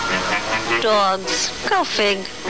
Voice Actor: Bernadette Peters
Rita and Runt talking on a slab